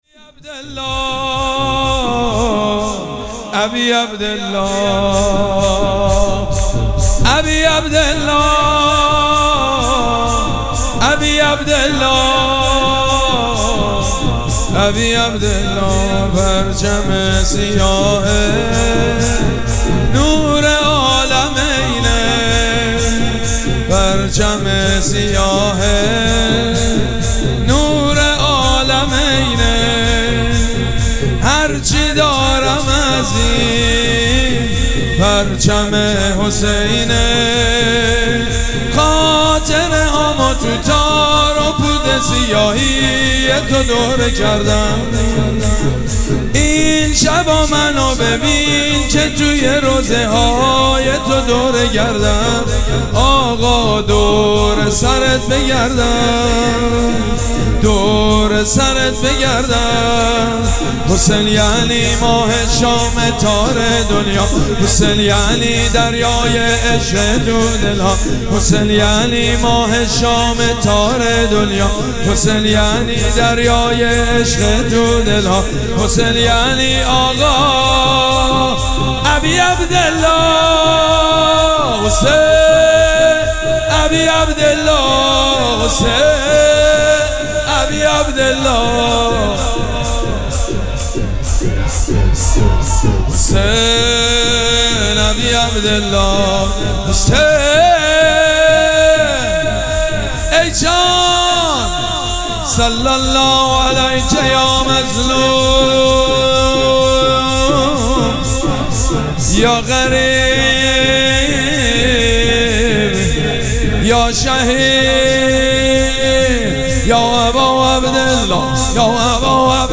شب پنجم مراسم عزاداری اربعین حسینی ۱۴۴۷
شور
حاج سید مجید بنی فاطمه